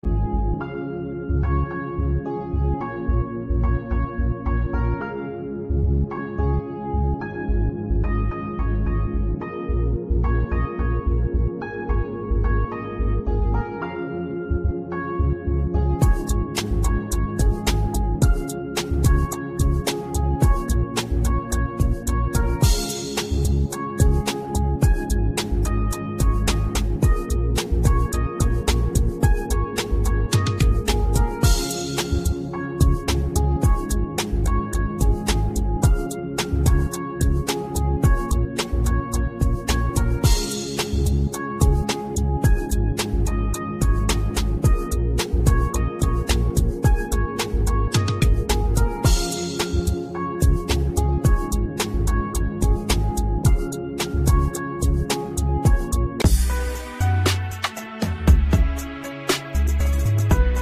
Romantic Relaxing Music